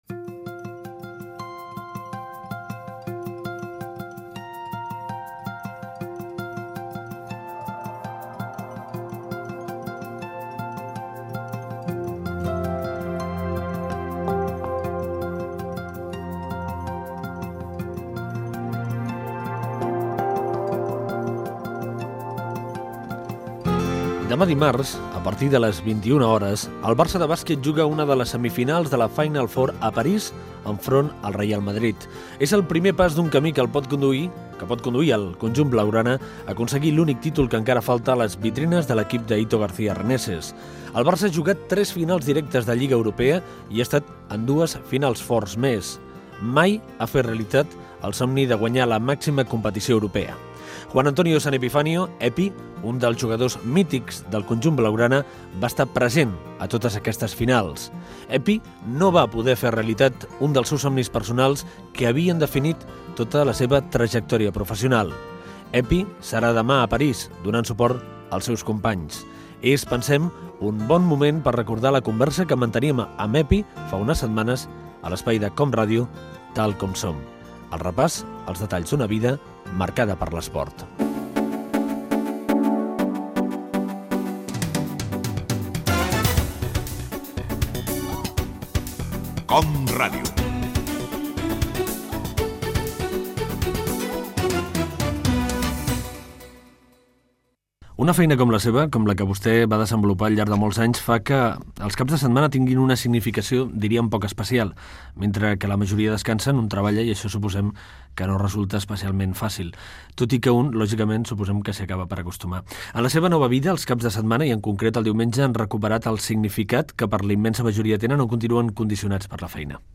Presentació i fragment d'una entrevista al jugador de bàsquet del Futbol Club Barcelona Juan Antonio San Epifanio "Epi"
Entreteniment